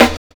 Snare set 2 010.wav